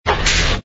engine_li_fighter_start.wav